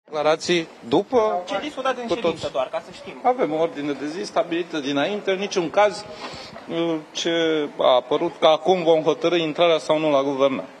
02iun-12-Grindeanu-va-zic-dupa-sedinta-ceva.mp3